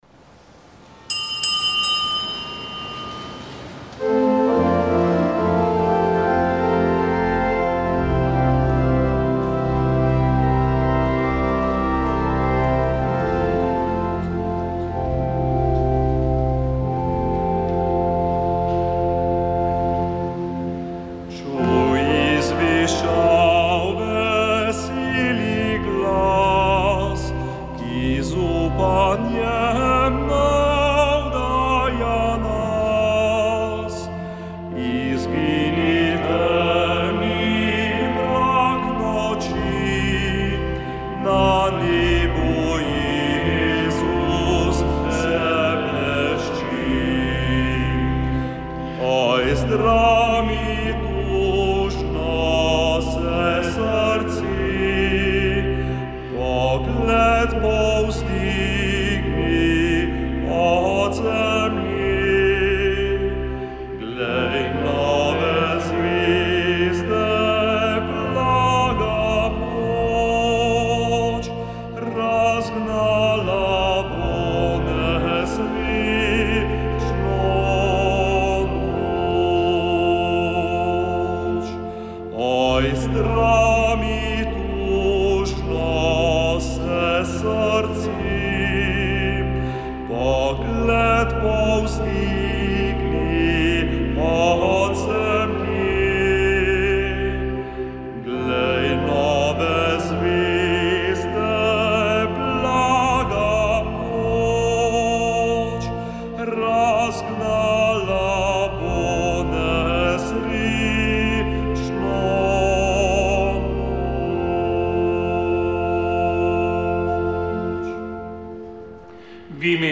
Sveta maša
Sv. maša iz stolne cerkve sv. Janeza Krstnika v Mariboru dne 22. 11.
Sveto mašo je daroval mariborski nadškof Alojzij Cvikl.